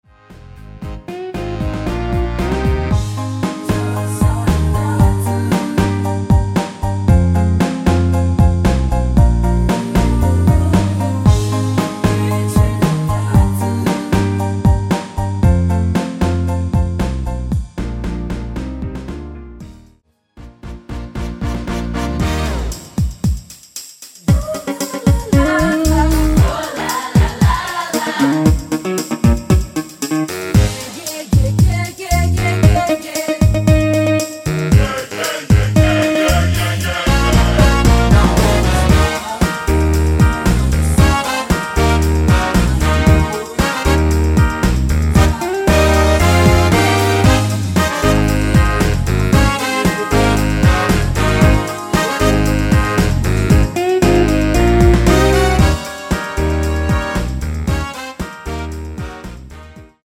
(-2) 내린코러스 포함된 MR 입니다.(미리듣기 참조)
Eb
앞부분30초, 뒷부분30초씩 편집해서 올려 드리고 있습니다.